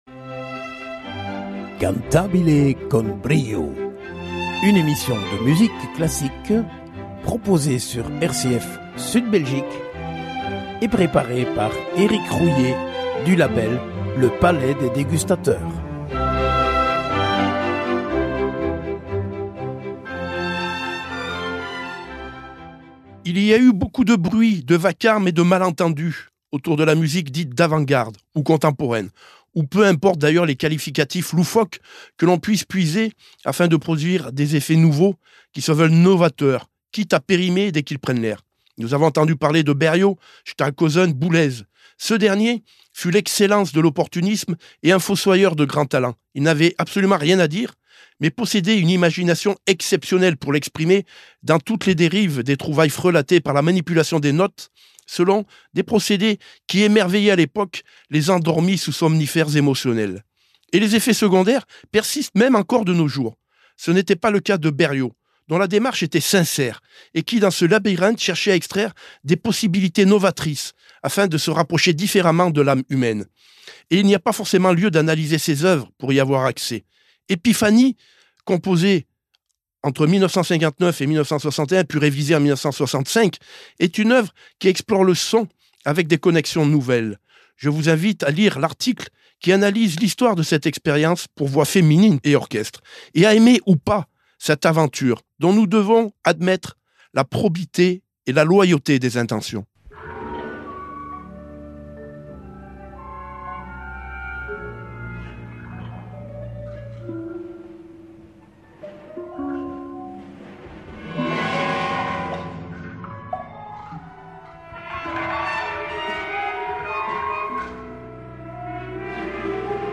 Cantabile con brio En partenariat avec le ” palais des dégustateurs ” nous vous proposons chaque semaine une émission de musique classique émaillée d’interviews exclusives.